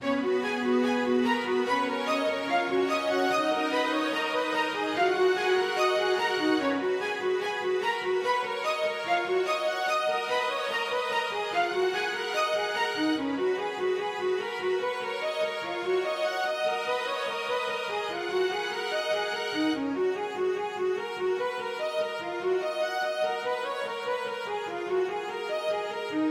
车顶上的雨
描述：在我的面包车的屋顶上记下了一小段雨。 用H4N记录
标签： 红宝石 暴风 天气 环境 瀑布 屋顶 金属 自然 格鲁吉亚
声道立体声